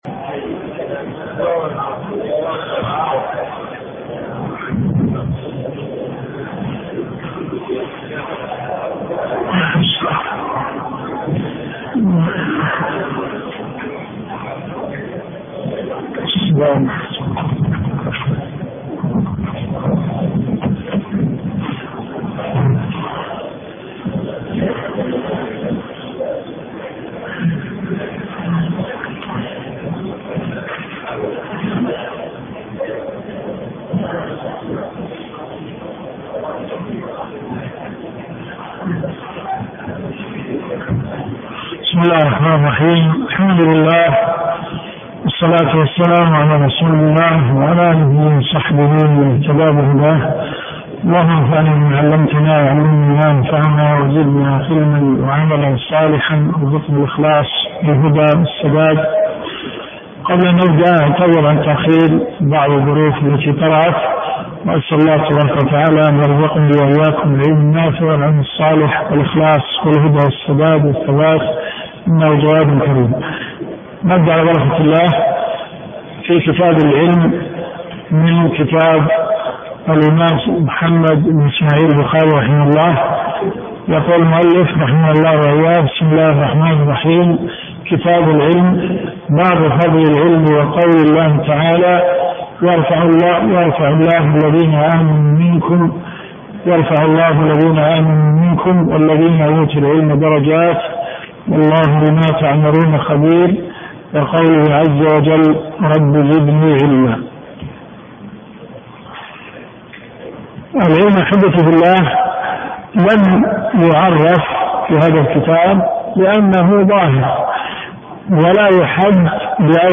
الدروس الشرعية
جامع البلوي